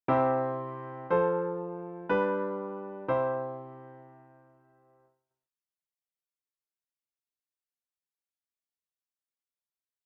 The Neapolitan chord takes the flat-II (Db-F-Ab), places the F in the bass, and it takes the place of an F chord that moves to G. So it turns this:
C  F  G  C into this: C  Db/F  G  C.